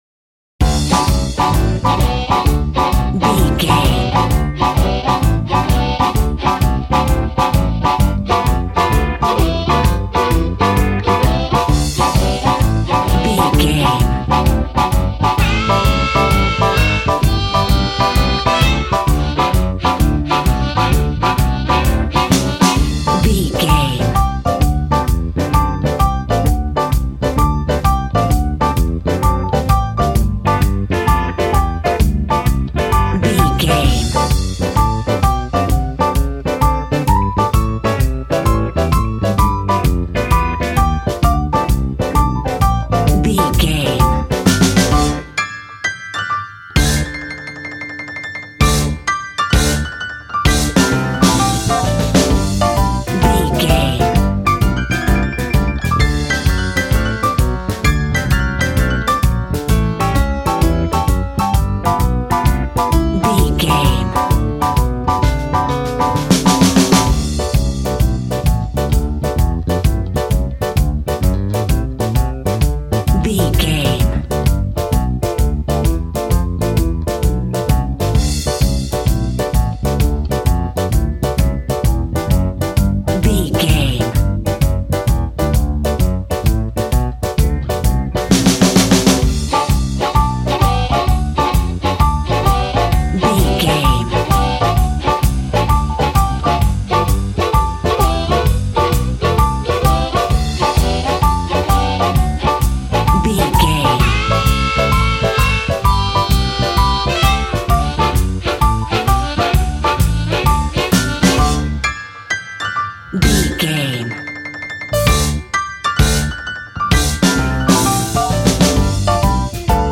Ionian/Major
D
sad
mournful
bass guitar
electric guitar
electric organ
drums